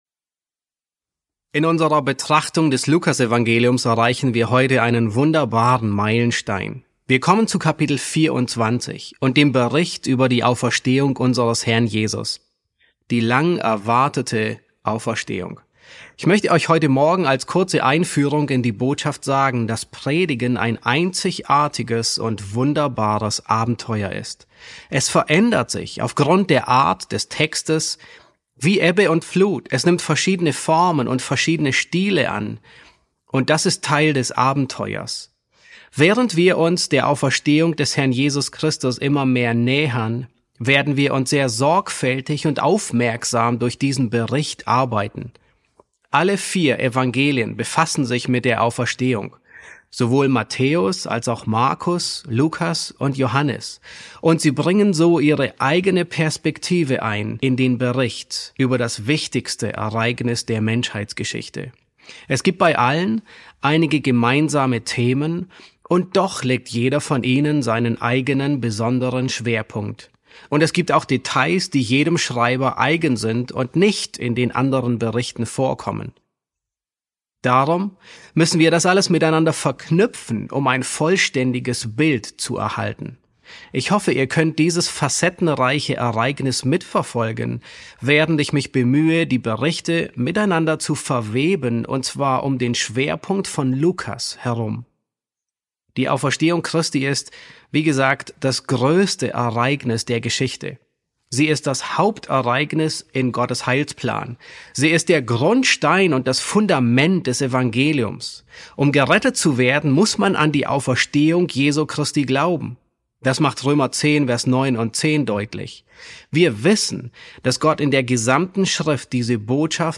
E2 S2 | Ein leeres Grab mit einer Erklärung von Engeln ~ John MacArthur Predigten auf Deutsch Podcast